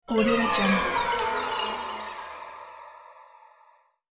دانلود افکت صدای باز کردن طلسم 1
افکت صدای باز کردن طلسم 1 یک گزینه عالی برای هر پروژه ای است که به صداهای بازی و جنبه های دیگر مانند جادو، طلسم و سیرک نیاز دارد.
Sample rate 16-Bit Stereo, 44.1 kHz
Looped No